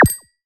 etfx_explosion_minimagic2.wav